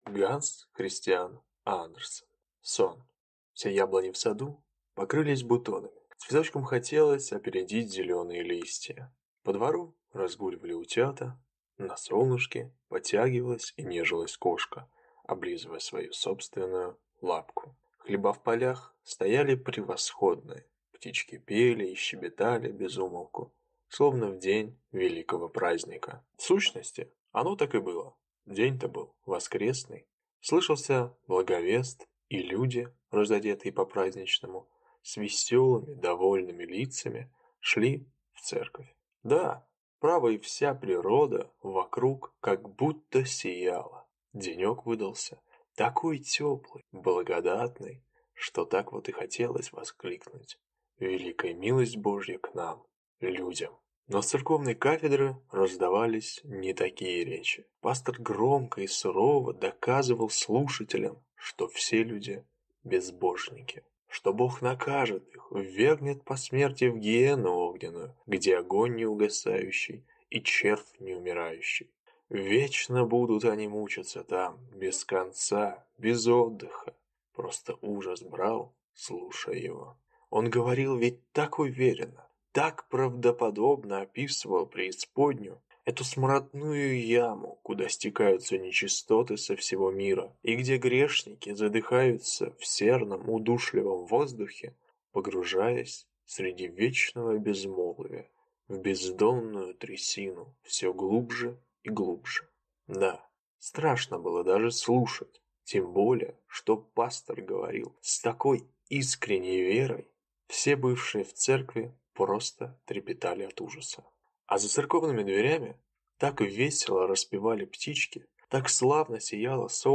Аудиокнига Сон | Библиотека аудиокниг